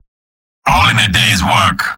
Robot-filtered lines from MvM. This is an audio clip from the game Team Fortress 2 .
Spy_mvm_cheers01.mp3